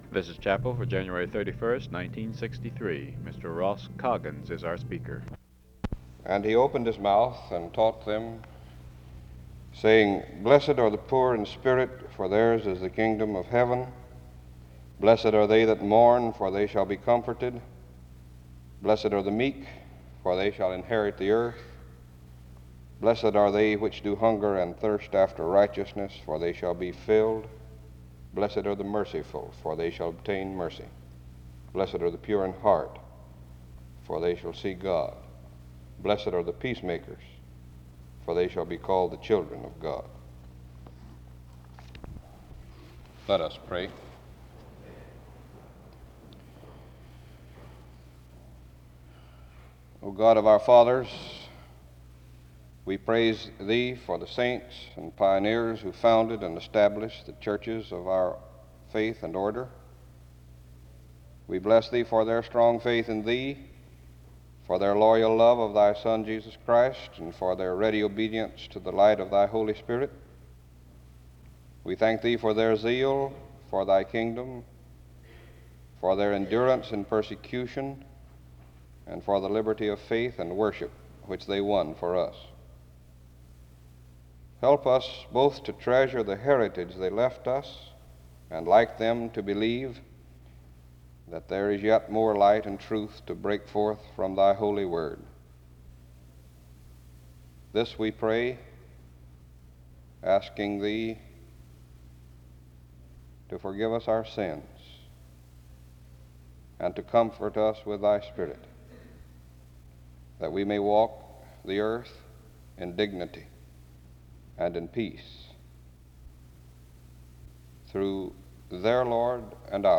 The service begins with a scripture reading of Matthew 5:2-9 and prayer from 0:00-2:09. There is a brief introduction to the speaker from 2:09-2:55.